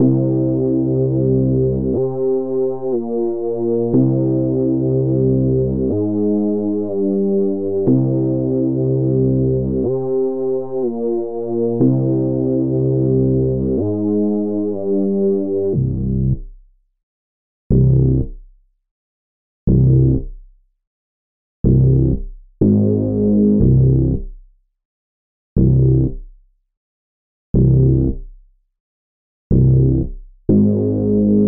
描述：低沉的，有范围的空间低音。
标签： 122 bpm Electronic Loops Bass Synth Loops 2.65 MB wav Key : Unknown
声道单声道